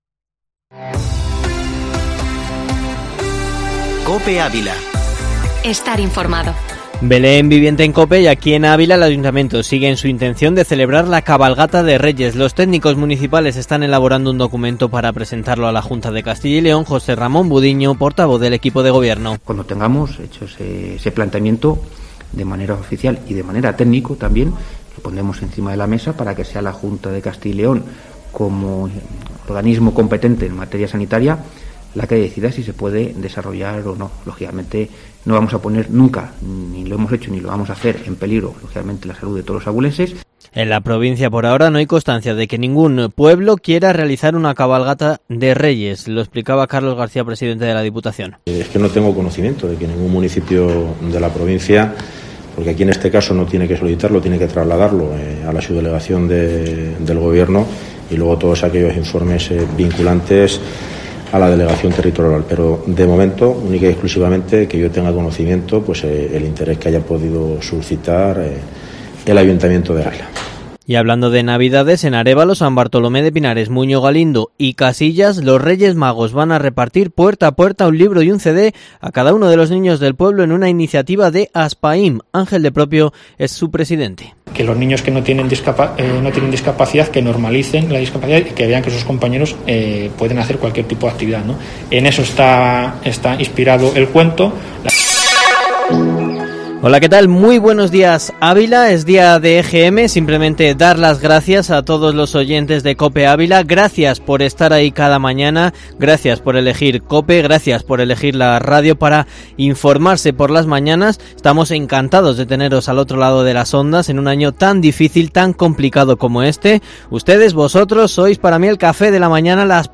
Informativo matinal Herrera en COPE Ávila 02/12/2020